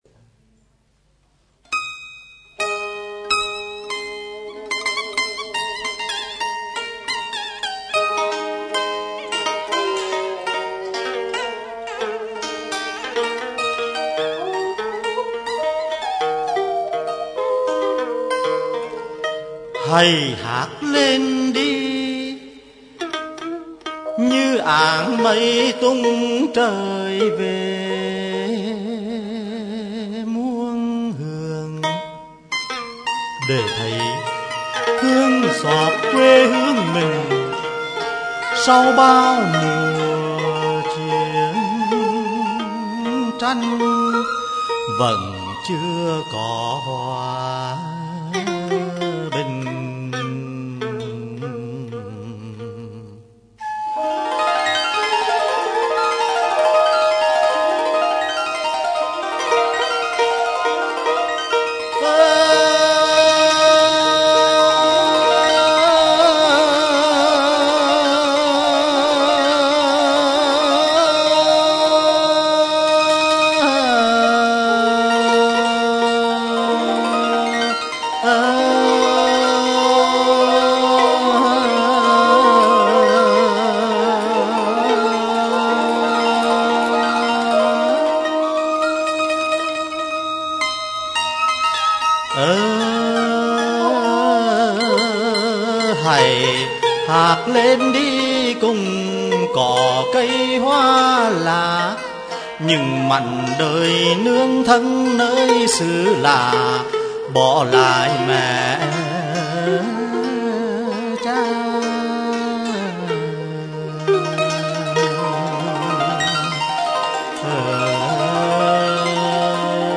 diễn ngâm